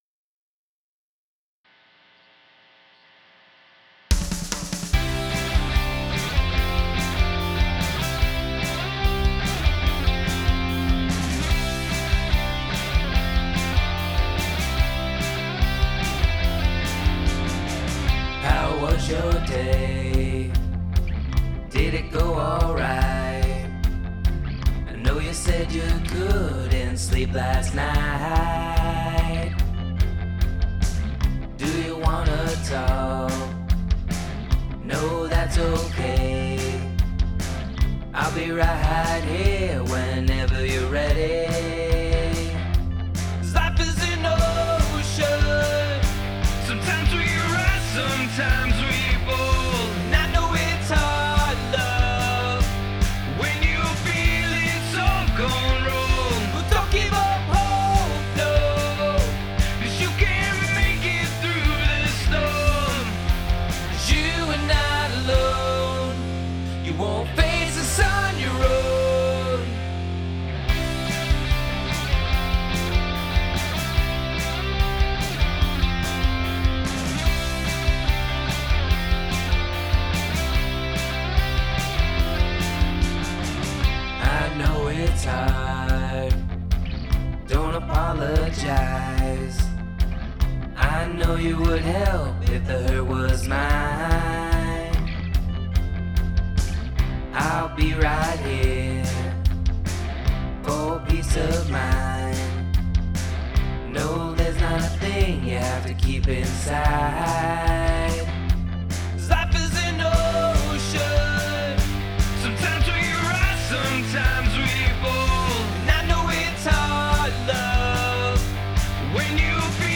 I've put together a mixdown for the opening track, Life is an Ocean and I'm hoping to get some feedback on the mix.
I'm generally working with guitar/bass/drums/vocals with the occasional synth or piano thrown in for fun.